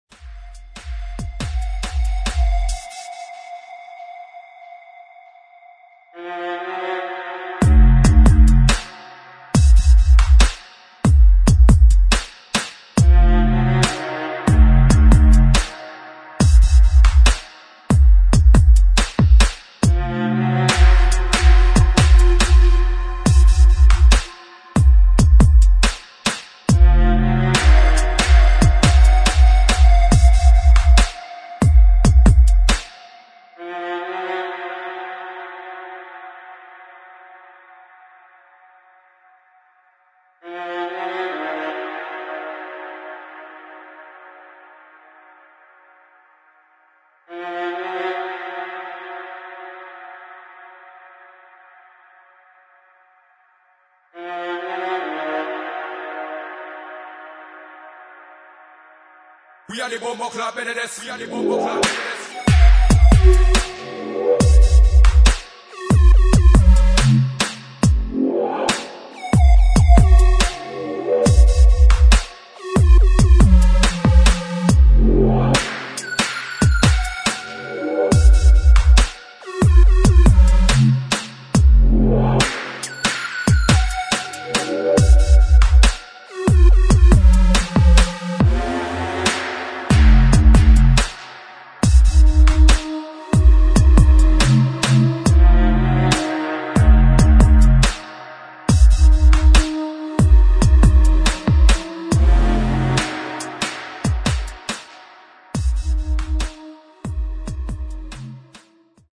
[ DUBSTEP / GRIME ]